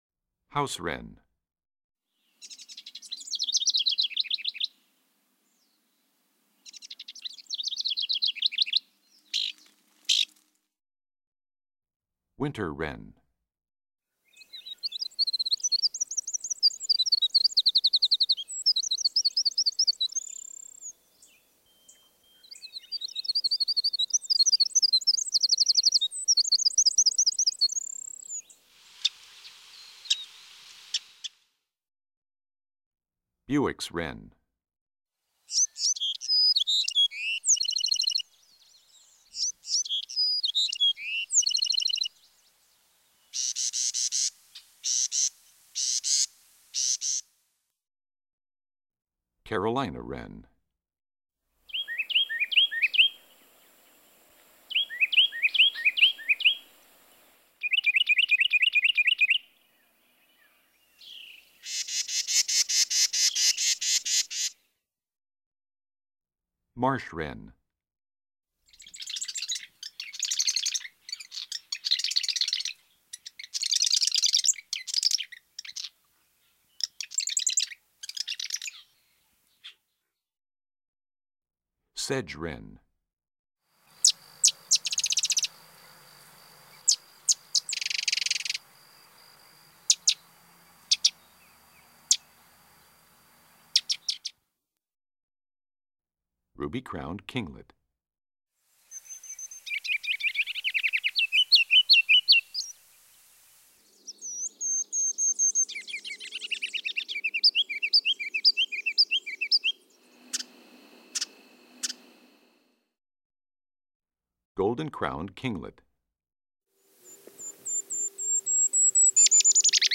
Index of /songs/Animals/Birds/Bird Songs Eastern-Central